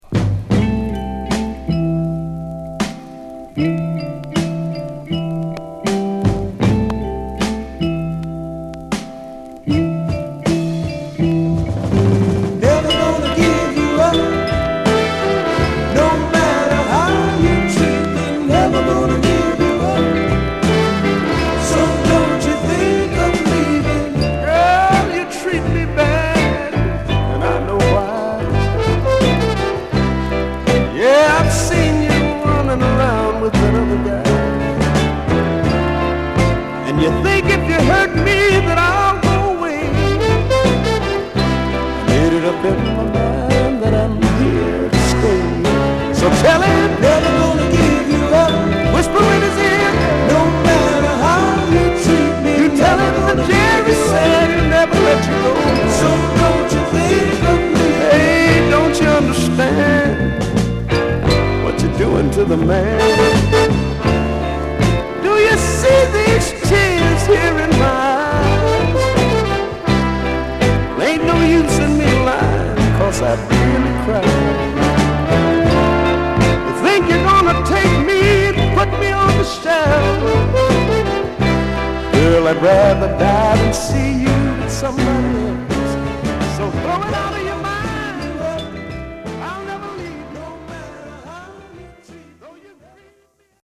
涙がこぼれ落ちそうなエエ曲です！！